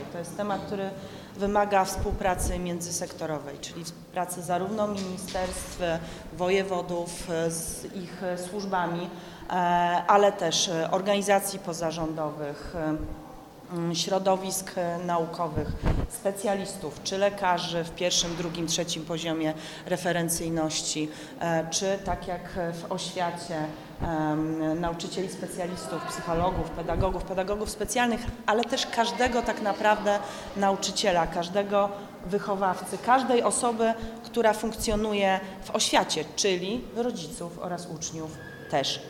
W trosce o zdrowie psychiczne dzieci i młodzieży na Dolnym Śląsku odbyła się w czwartek (12 czerwca) Wojewódzka Konferencja dla dyrektorów szkół i nauczycieli, poświęcona przeciwdziałaniu kryzysom emocjonalnym wśród najmłodszych.
Ministerstwie Edukacji Narodowej, która przypomniała, że kwestia kryzysów emocjonalnych uczniów dotyczy też rodziców.